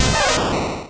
Cri de Pikachu dans Pokémon Rouge et Bleu.